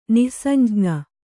♪ nih samjña